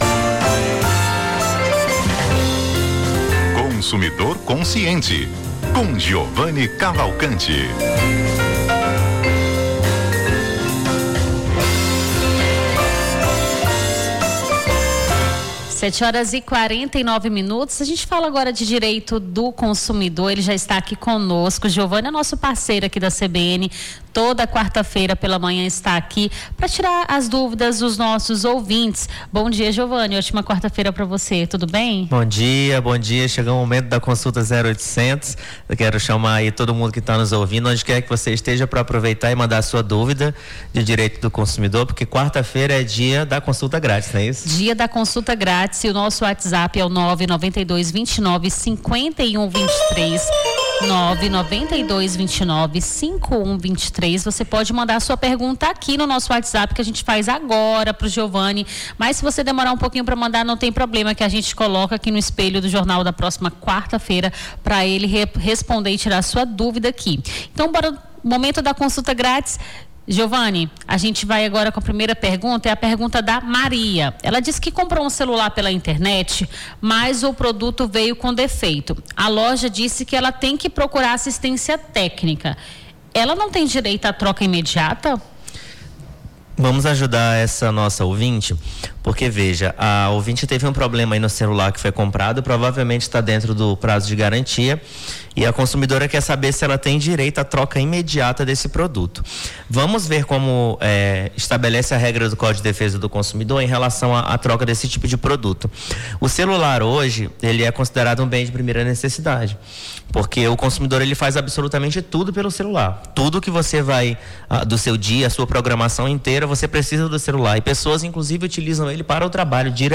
Consumidor consciente: advogado tira-dúvidas dos ouvintes sobre direito do consumidor
Nome do Artista - CENSURA - COLUNA (CONSUMIDOR CONSCIENTE) 20-08-25.mp3